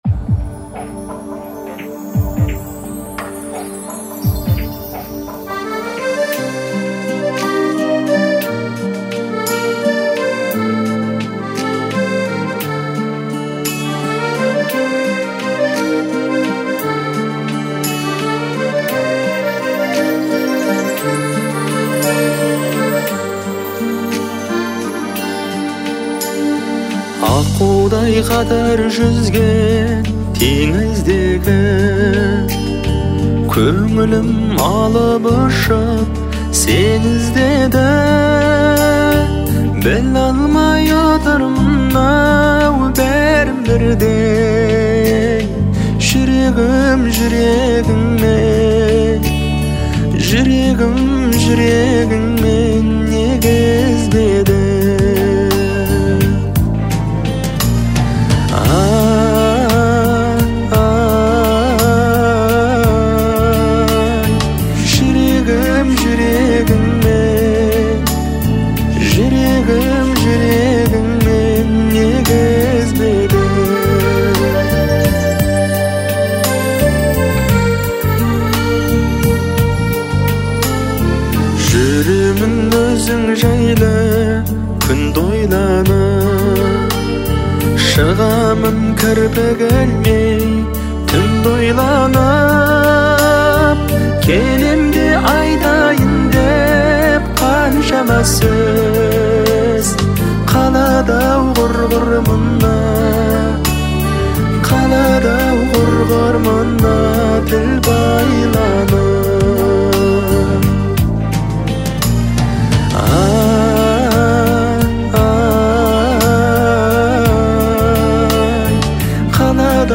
это трогательная казахская песня в жанре поп